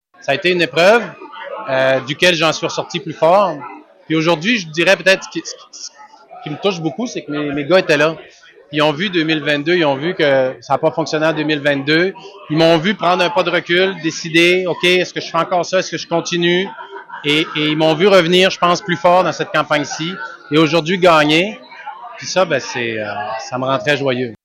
En entrevue sur les ondes de Radio Gaspésie, il relate que sa tentative ratée de se faire élire comme député péquiste de Bonaventure lors des dernières élections provinciales a été difficile.